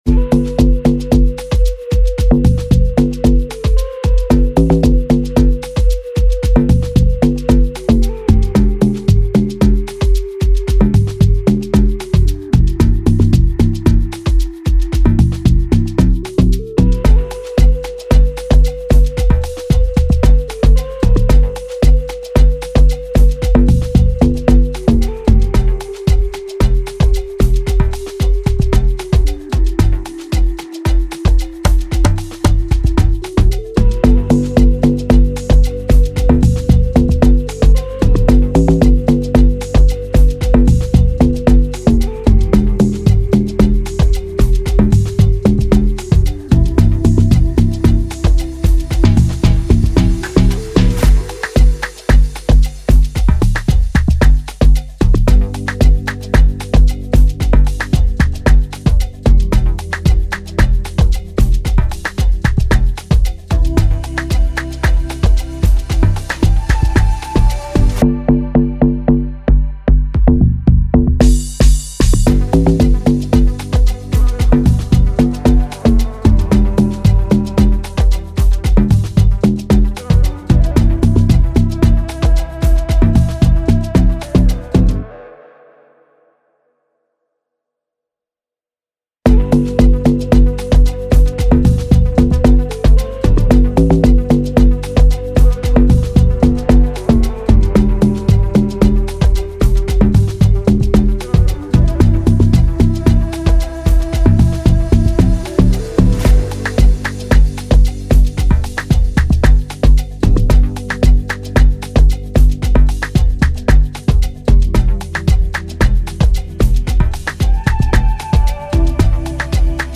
2025 in Dancehall/Afrobeats Instrumentals